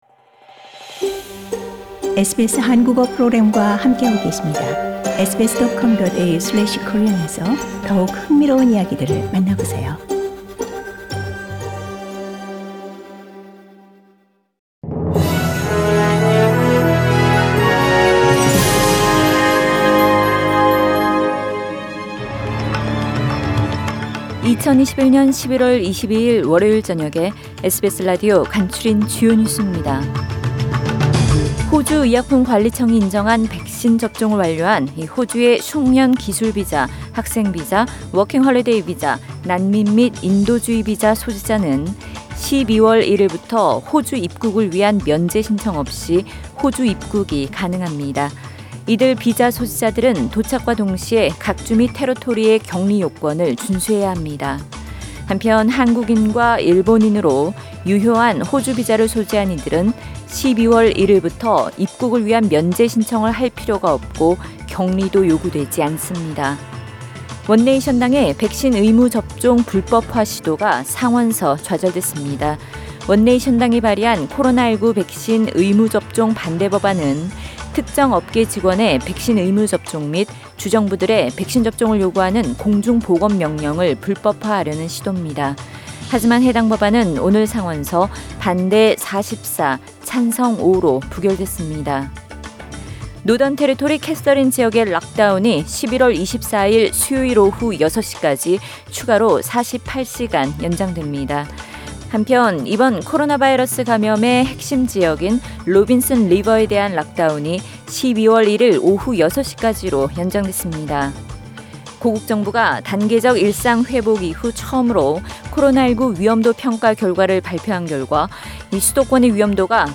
SBS News Outlines…2021년 11월 22일 저녁 주요 뉴스